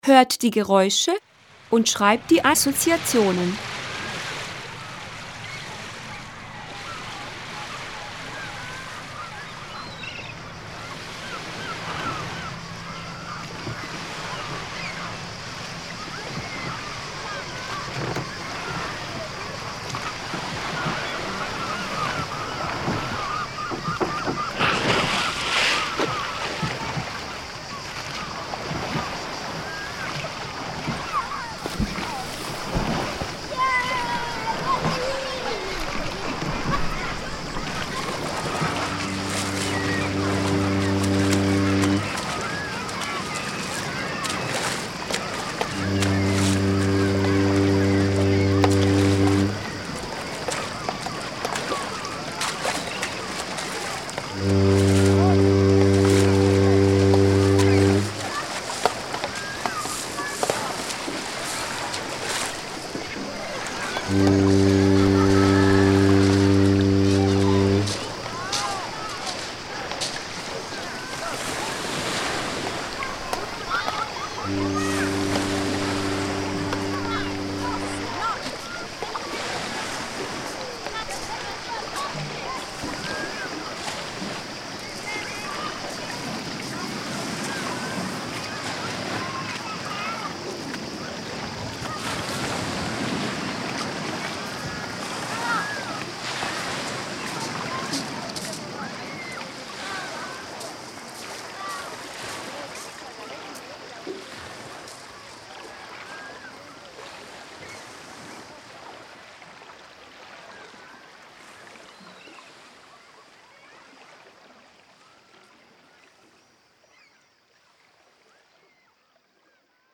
Die Sommerferien sind vorbei 8. razred, peta godina u�enja Rad s razrednim plakatom nastalim na zadnjem nastavnom satu u sedmom razredu. Prilo�eni dokumenti: METODICKA_PREPORUKA_Die_Sommerferien_sind_vorbei_8_razred.pdf (785.90 KB) Prilog_2.docx (44.24 KB) Sommerger�usche.mp3 (3.82 MB) [ Povratak | Ispi�i �lanak | Po�alji prijatelju ]